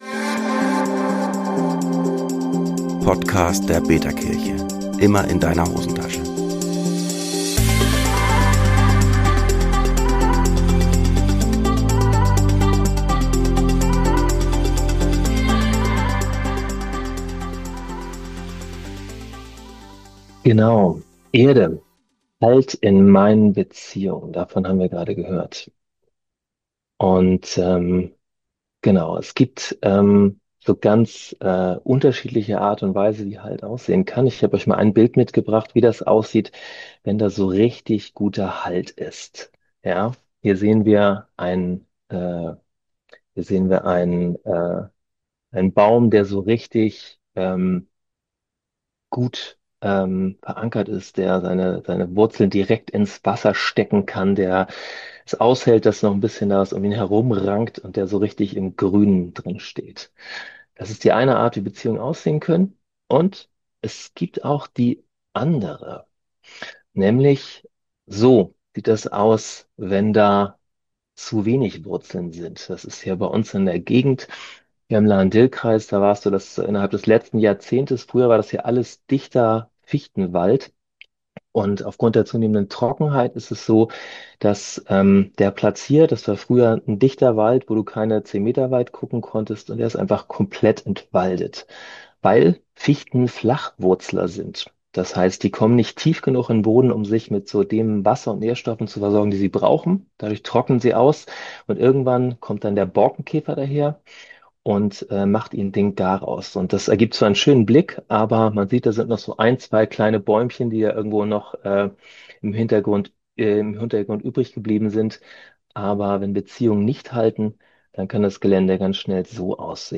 Gottesdienst 4 elements